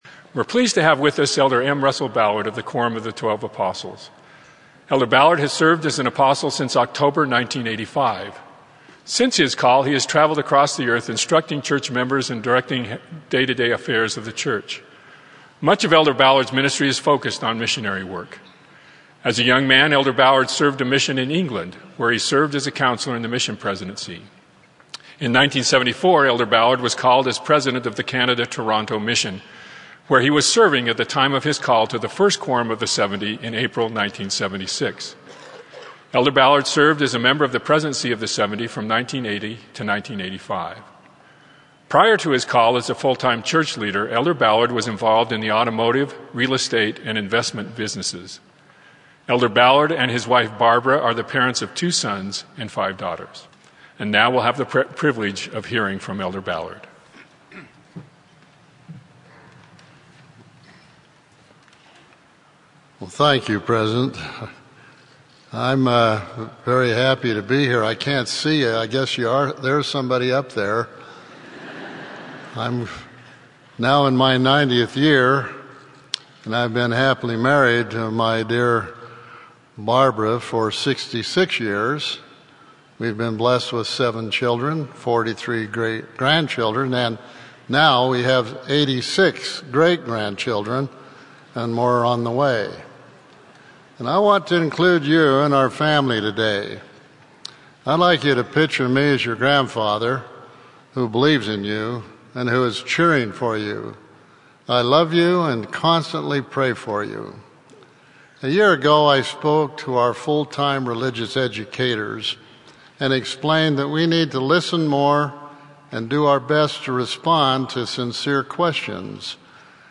of the Quorum of the Twelve Apostles